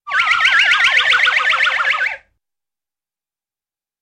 Голова крутится со свистом звук